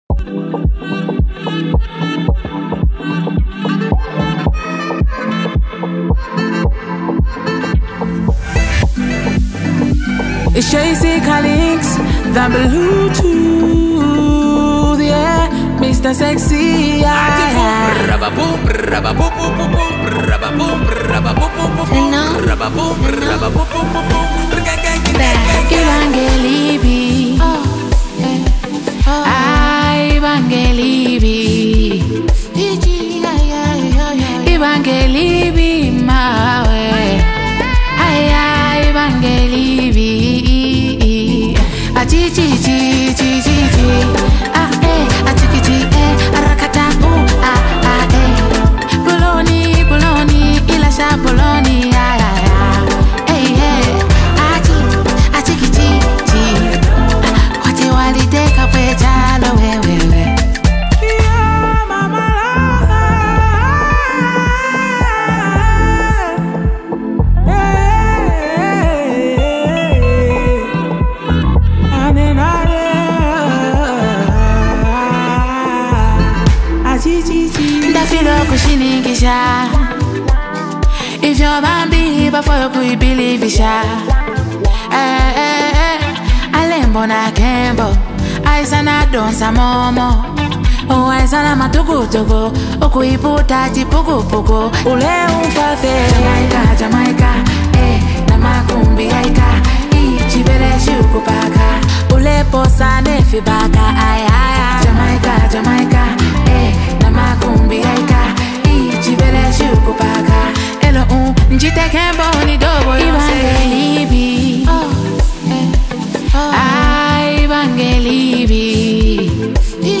smooth vibe